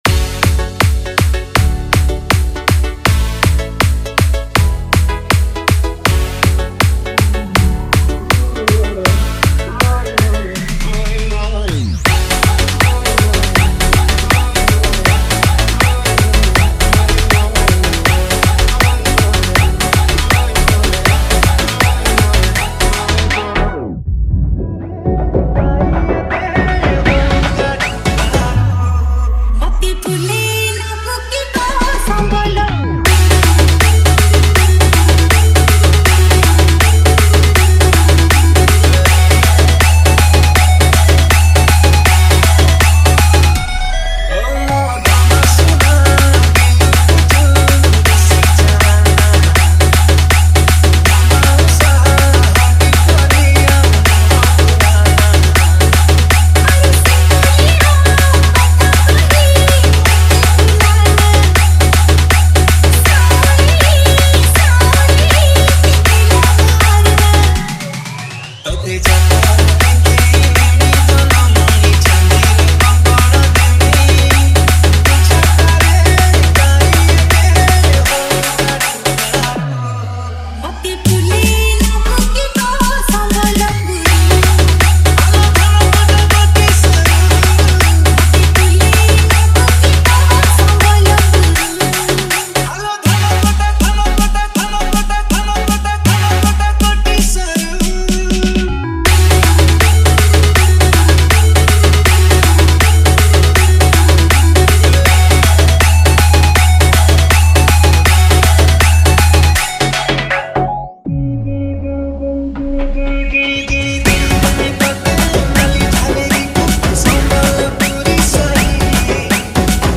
Sambalpuri Dj Song 2024